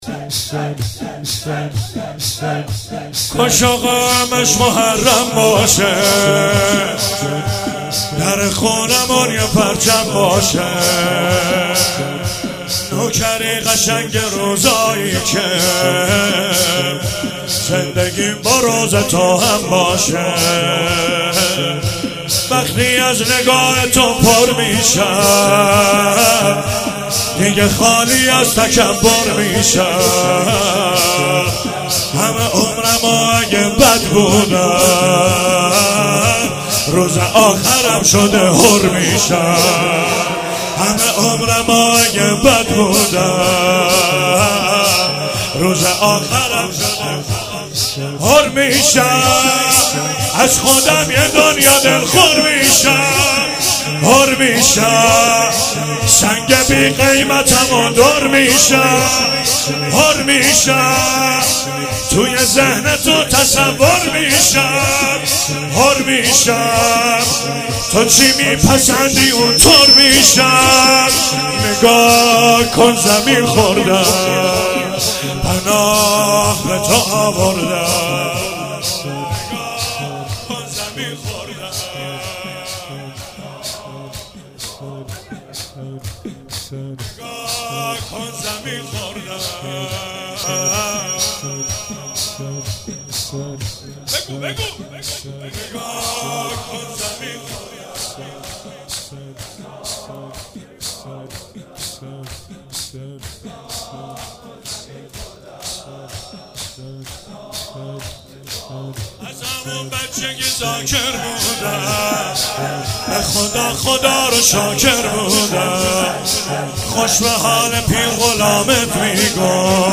روضه
03 heiat alamdar mashhad.mp3